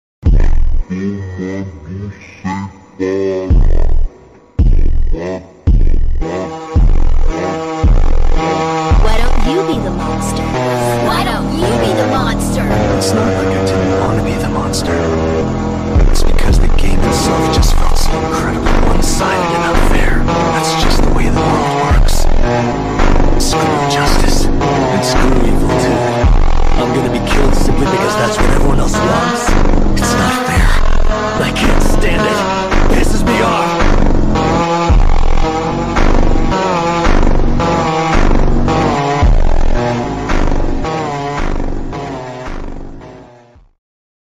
ULTRA SLOWED ) BASS BOOSTED 🎧 BRAZILIAN 🇧🇷 PHONK